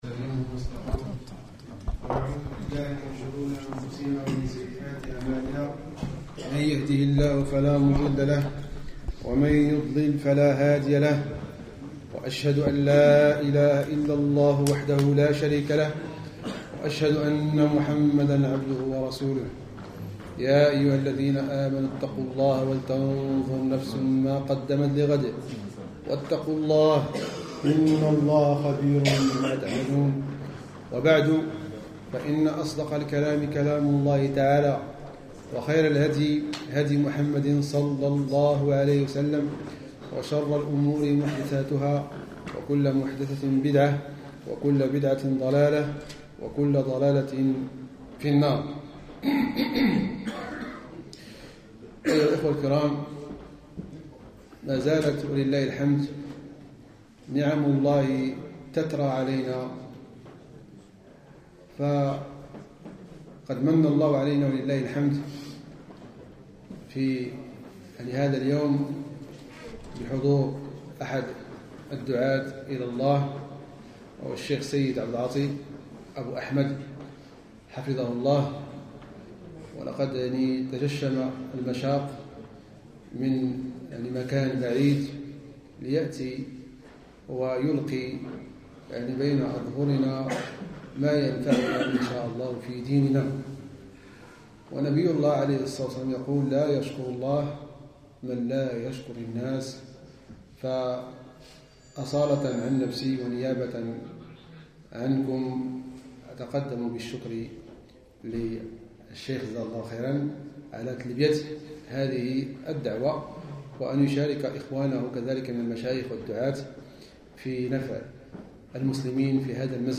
مسجد الإمام مالك بمدينة آخن - ألمانيا